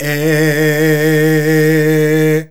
AEAEAEH A#.wav